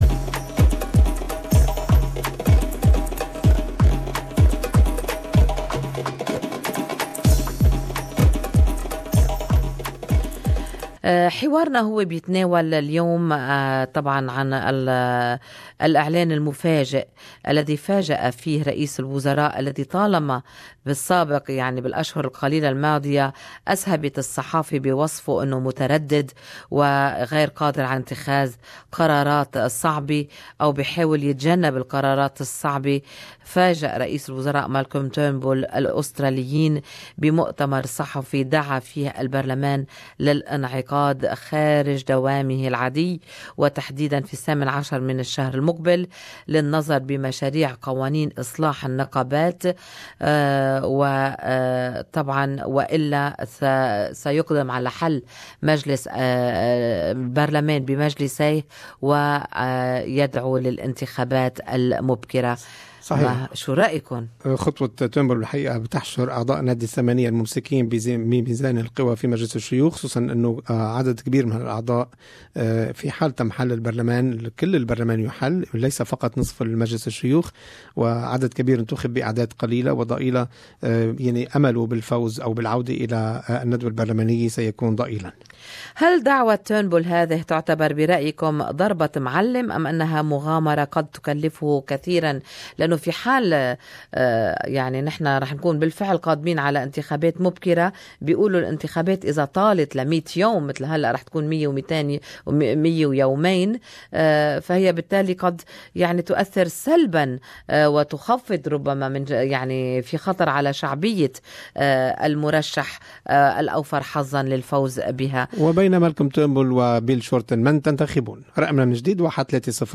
Talkback listeners opinions .